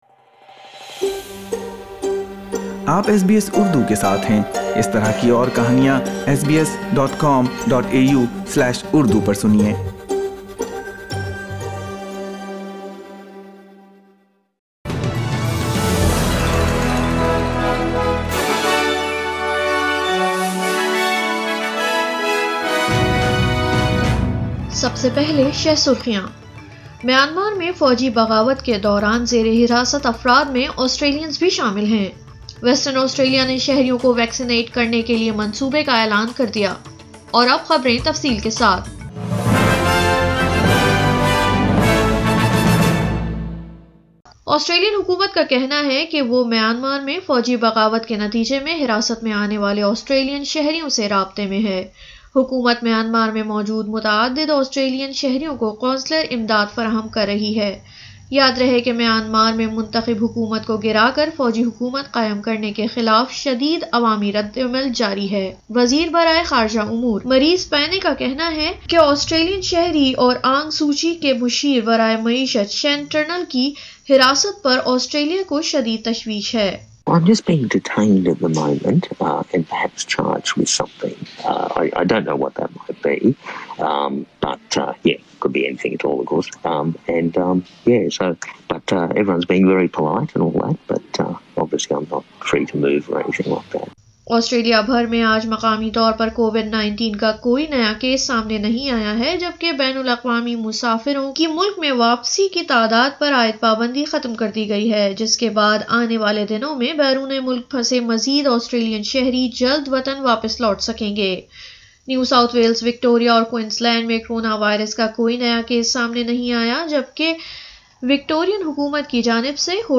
اردو خبریں 7 فروری 2020